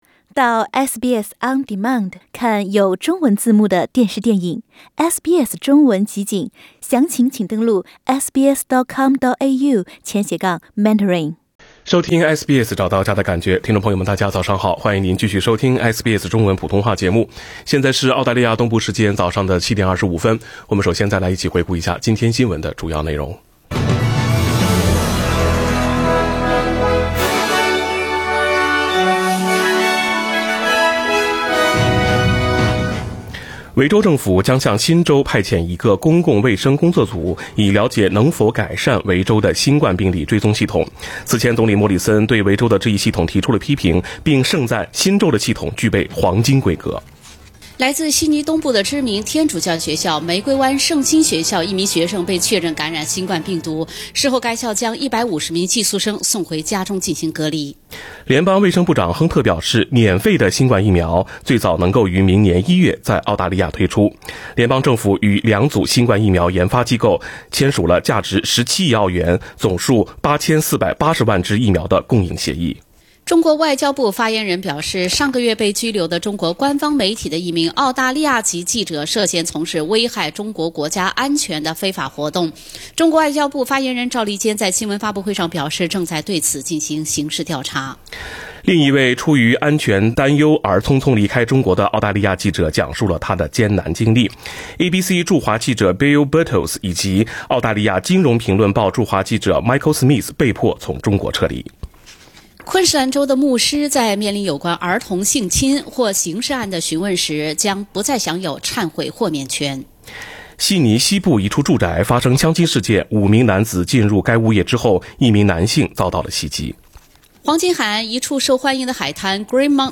SBS早新闻（9月9日）